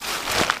MISC Newspaper, Scrape 01.wav